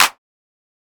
kits/OZ/Claps/Clap (Fresh).wav at ts
Clap (Fresh).wav